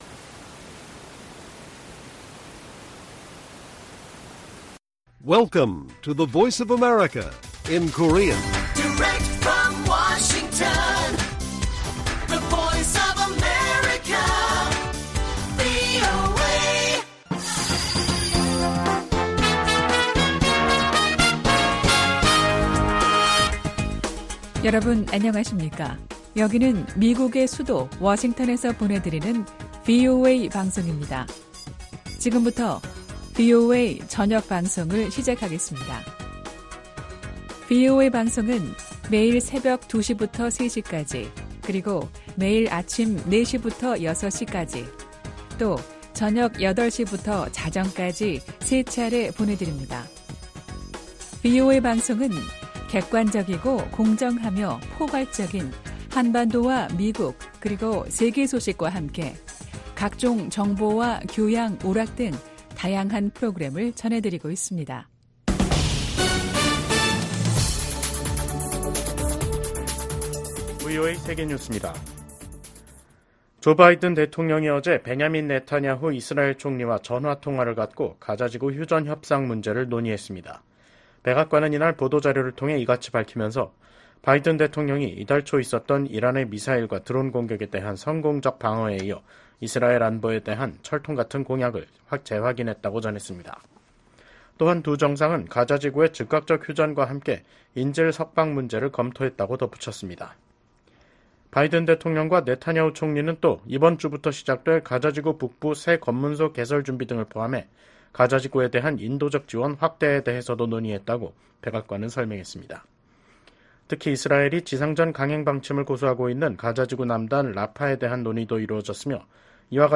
VOA 한국어 간판 뉴스 프로그램 '뉴스 투데이', 2024년 4월 29일 1부 방송입니다. 토니 블링컨 미 국무장관이 시진핑 중국 국가주석을 만나 북한이 도발을 중단하도록 대북 영향력을 행사할 것을 촉구했다고 밝혔습니다. 한국 대통령실은 북한 비핵화 협상에서 ‘중간 단계’(interim steps)’는 없다는 미국측 입장을 확인했다고 밝혔습니다. 미국이 이달 말 활동이 종료되는 유엔 대북제재 전문가패널의 대안을 반드시 찾아낼 것이라는 의지를 밝혔습니다.